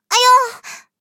M10狼獾小破语音1.OGG